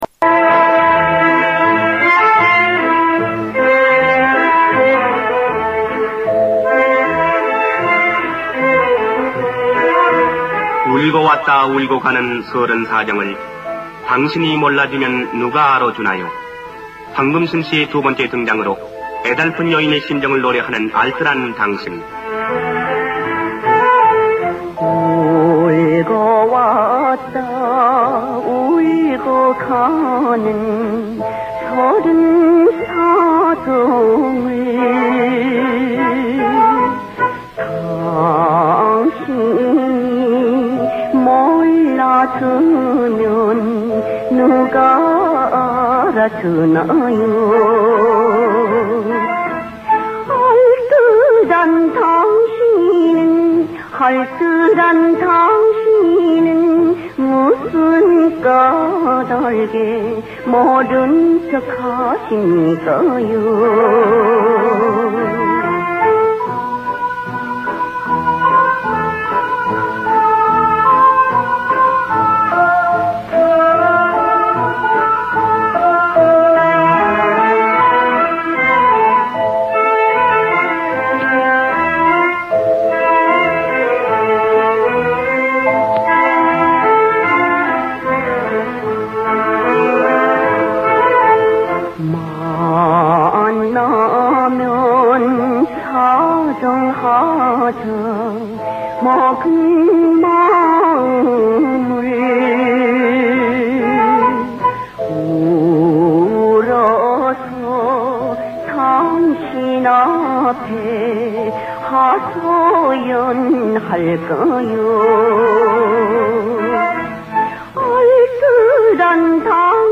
평가가 말해주듯이 님의 특유한 아름답고 정겨운 목소리는